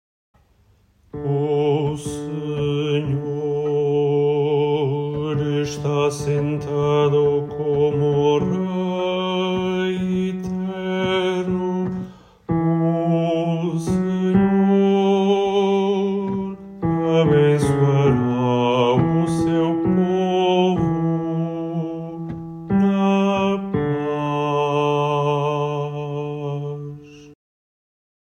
Baixo